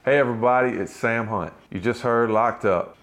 LINER Sam Hunt (you just heard Locked Up)